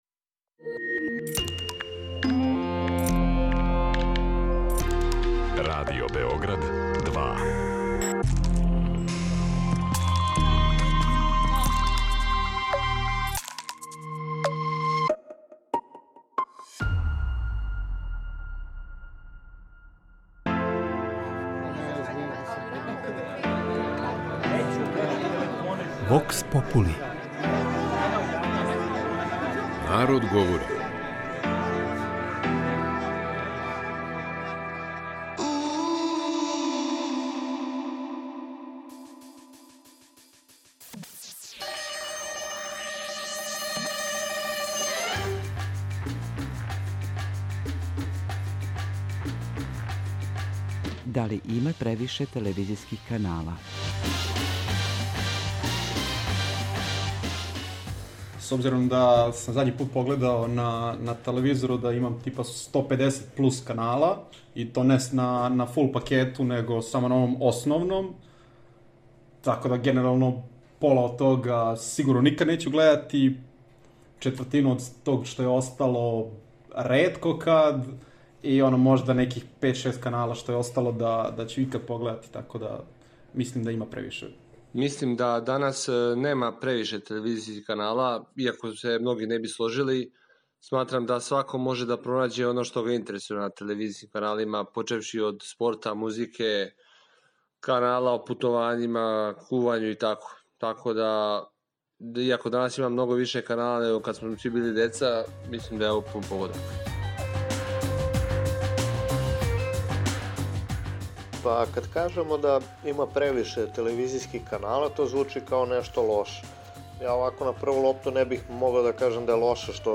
Успевате ли да у тренутној понуди телевизијских канала пронађете оно што вас интересује? У данашњој емисији питали смо наше суграђане да ли има превише телевизијских канала?
Вокс попули